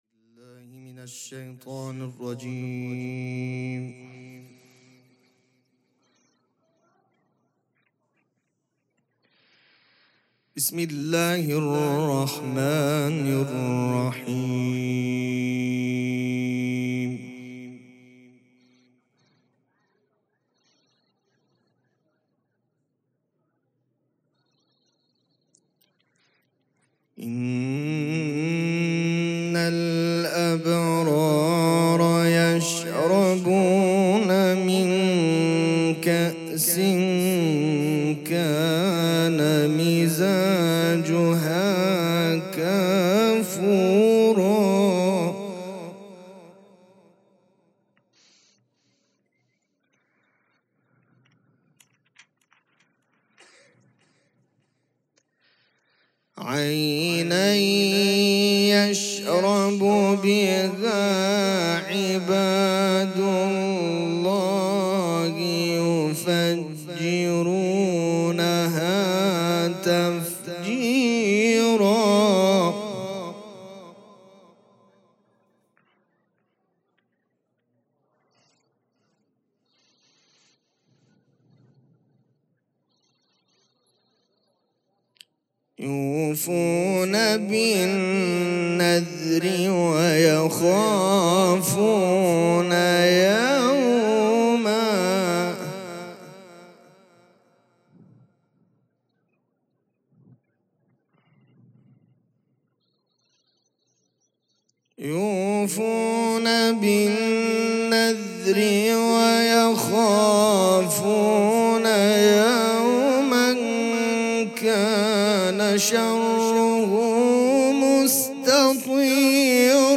قرائت قرآن کریم
قرائت قرآن